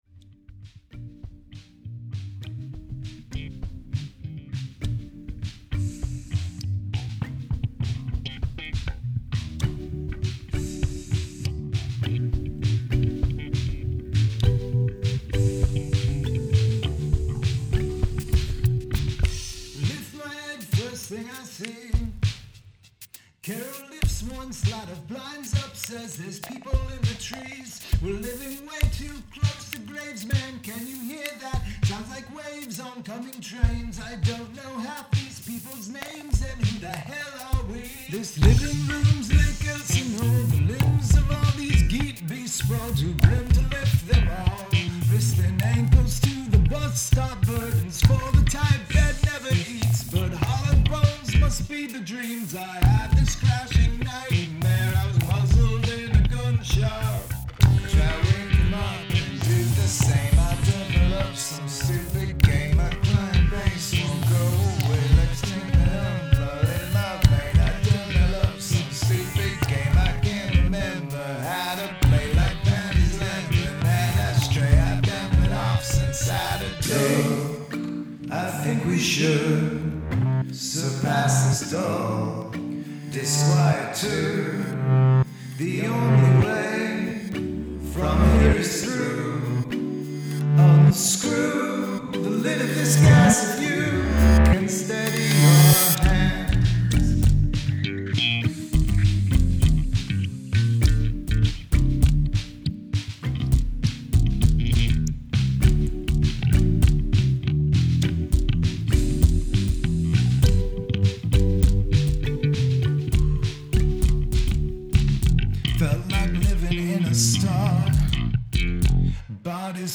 Have a guest play a household item on the track
The challenge is loud and hard panned.